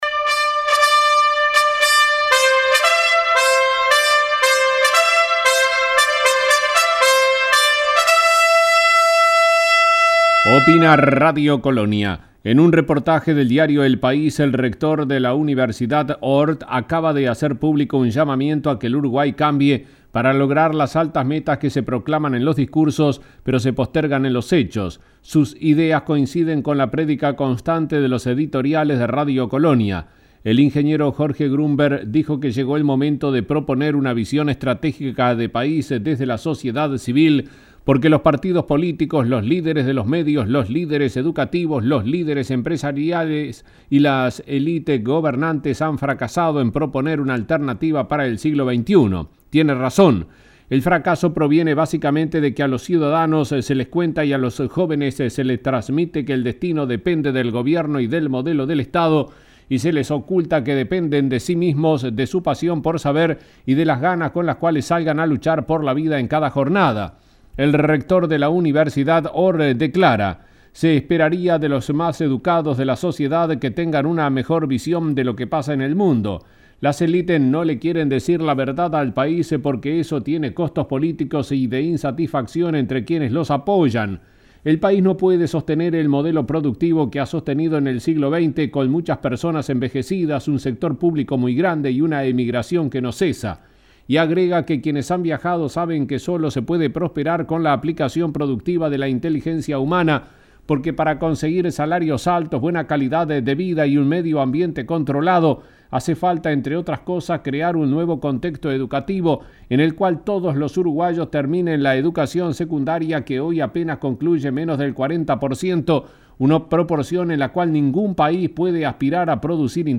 Editorial de Radio Colonia